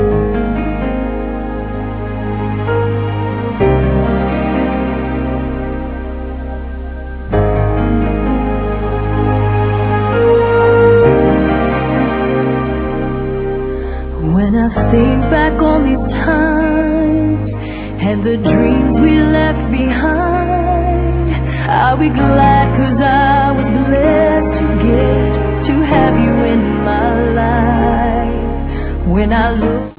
canzone dei titoli di coda